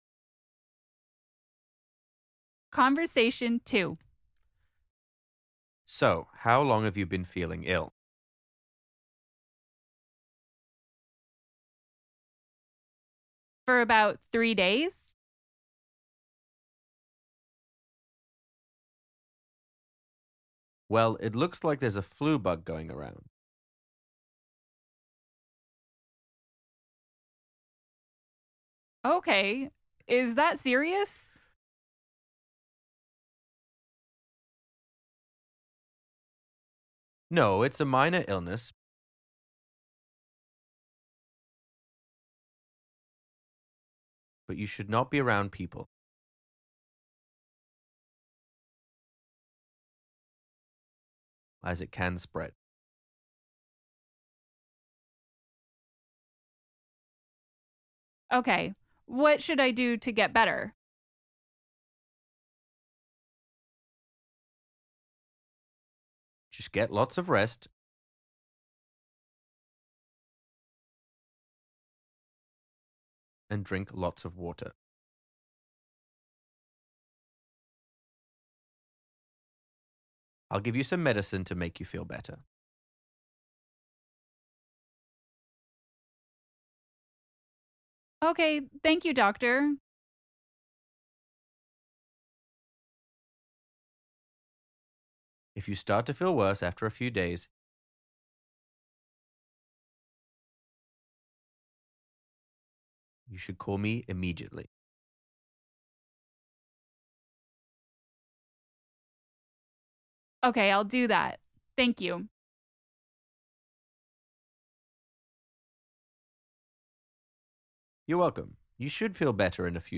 Man: So, how long have you been feeling ill?